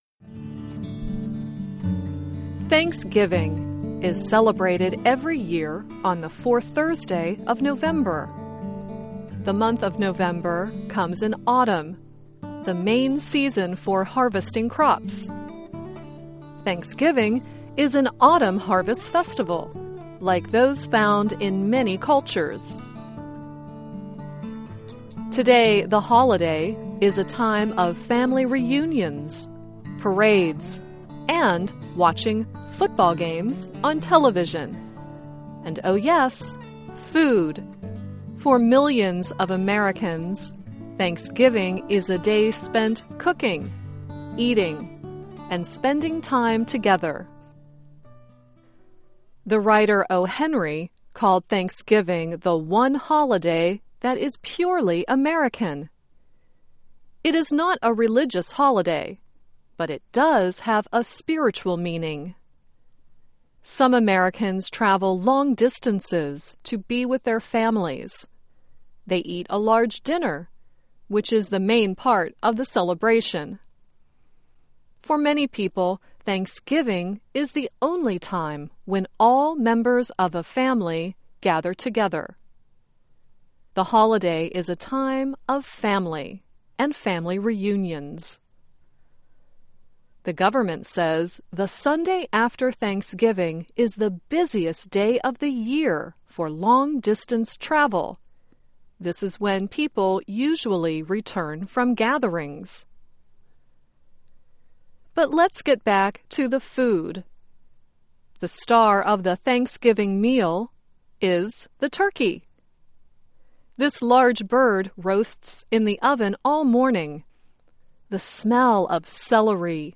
The production music is titled “The Holly and the Ivy” and is not available for redistribution.